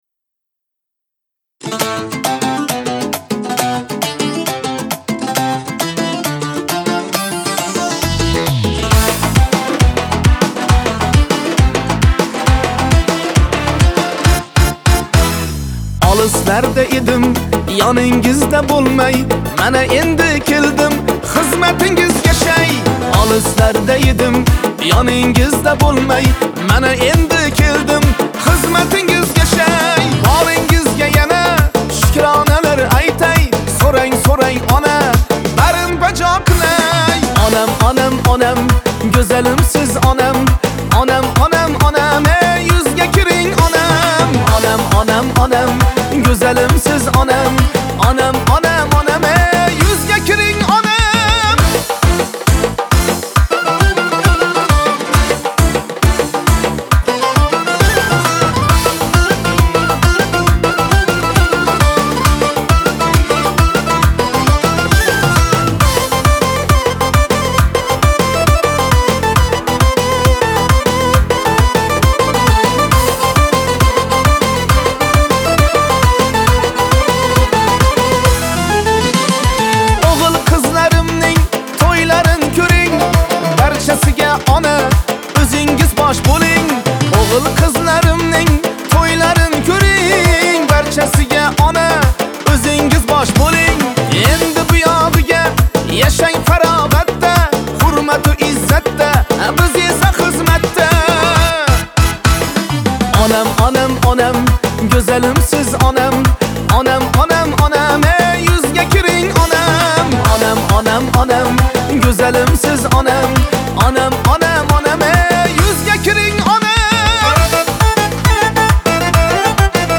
Трек размещён в разделе Узбекская музыка / Поп.